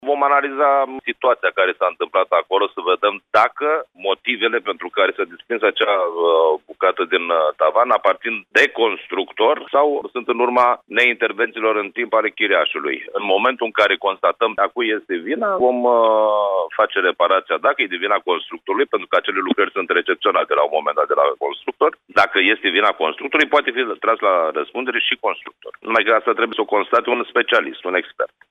Primarul de Botoșani, Cătălin Flutur, a declarat, pentru postul nostru de radio, că se va verifica dacă a fost afectată structura de rezistență a clădirii și cine se face vinovat de acest incident: constructorul sau chiriașul care nu a făcut lucrări de întreținere.